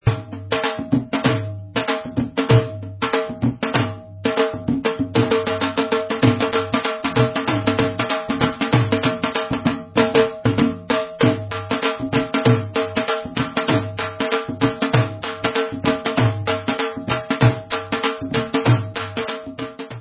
Dhol Pattern 4